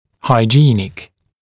[haɪ’ʤiːnɪk][хай’джи:ник]гигиенический